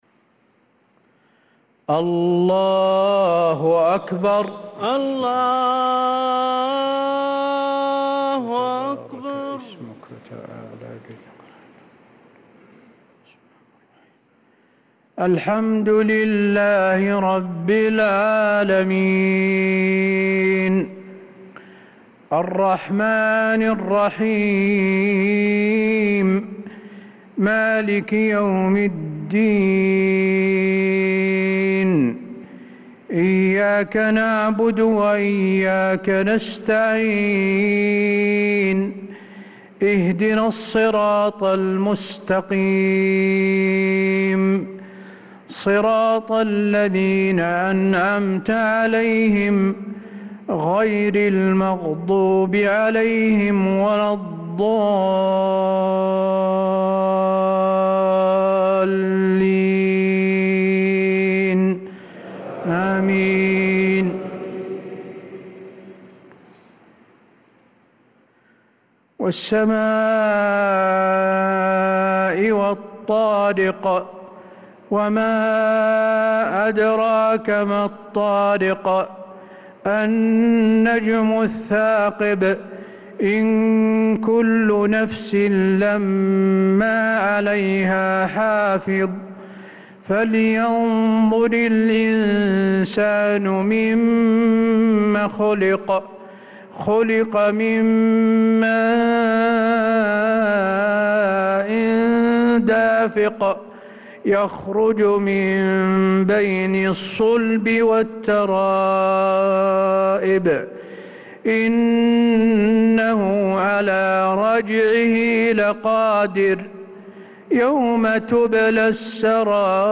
صلاة العشاء للقارئ حسين آل الشيخ 28 محرم 1446 هـ
تِلَاوَات الْحَرَمَيْن .